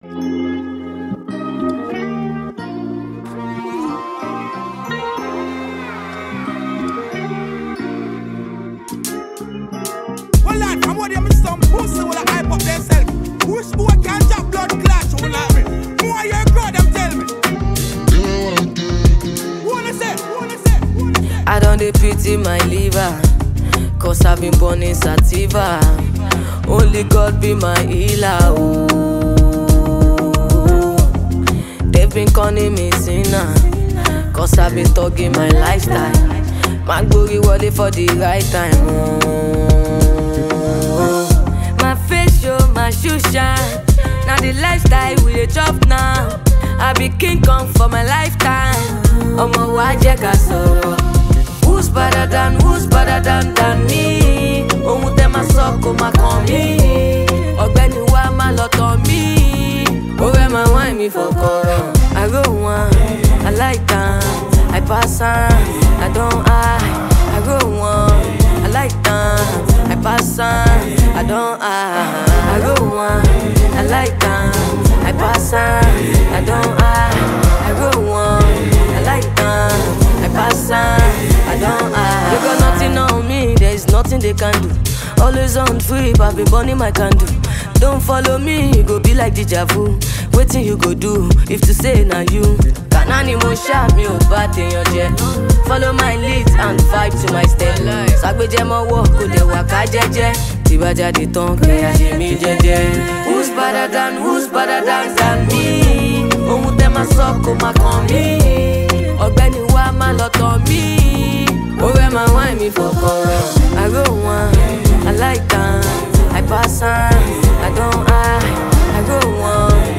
Nigerian street-hop
blends heartfelt lyrics with catchy melodies